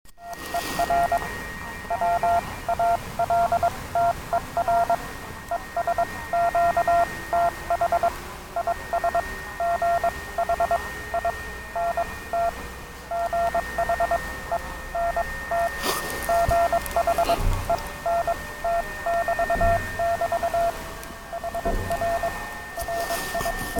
Прилагаю парочку коротких аудио- записей, как работает приёмник
прямого преобразования в реальном эфире, в лесах- полях.
Gromkaja_STN_na_TPP.mp3